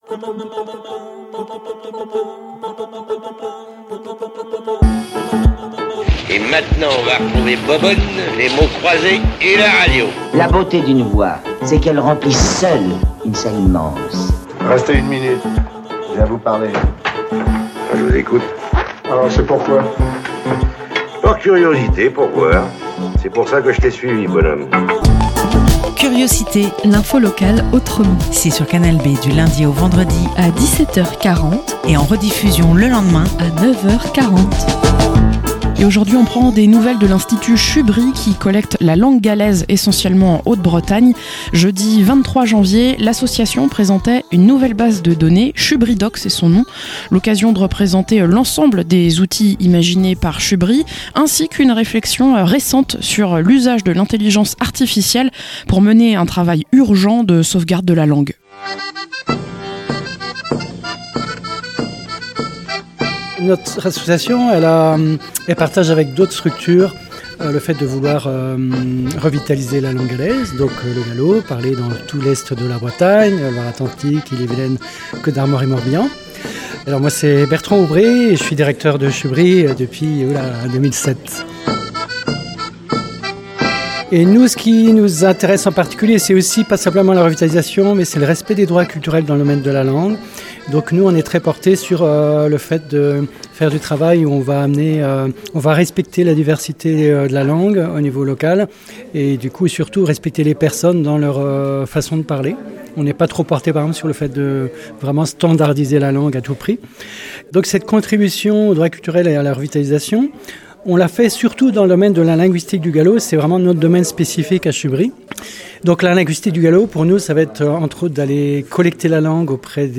- Reportage lors du lancement de Chubridoq, une base de données pour le gallo. L'occasion de représenter cette association qui mène un travail urgent de collecte linguistique.